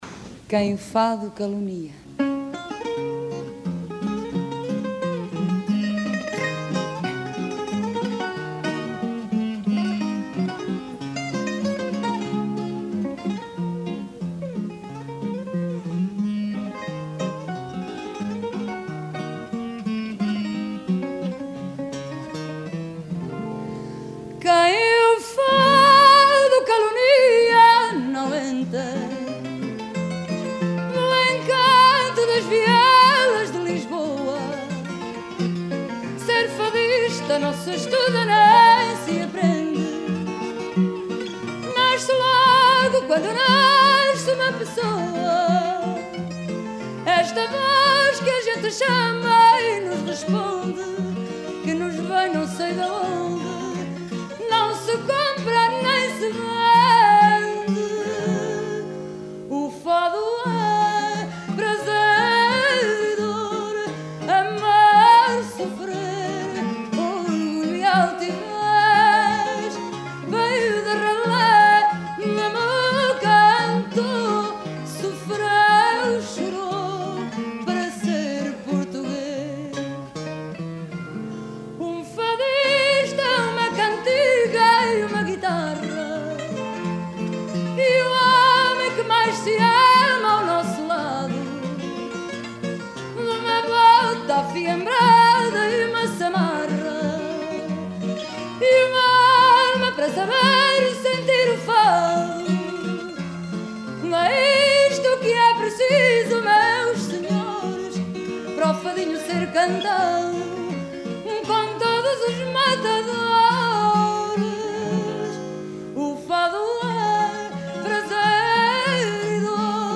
chant
guitare portugaise